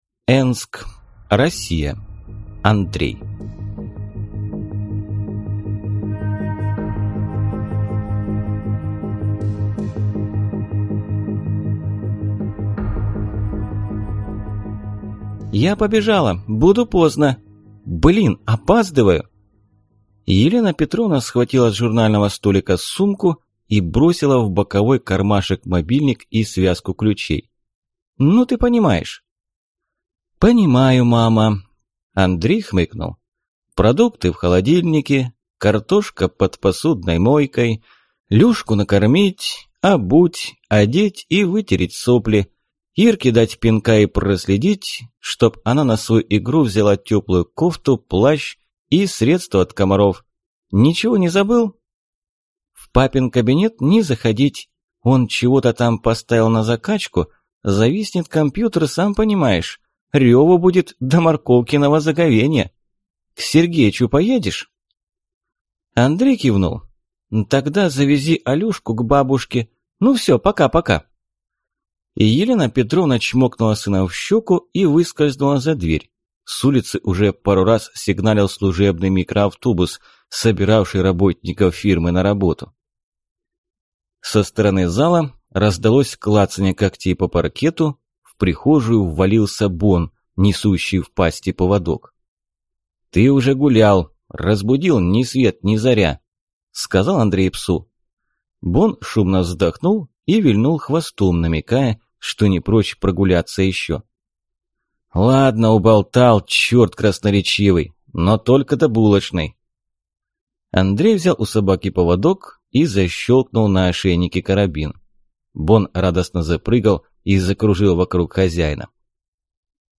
Аудиокнига Я – дракон | Библиотека аудиокниг